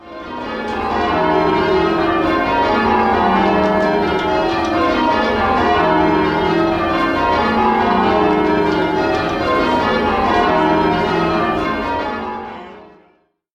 Home Bells
Inscriptions and weights after re-tuning in 1938 (in the key of E flat)
Rounds.mp3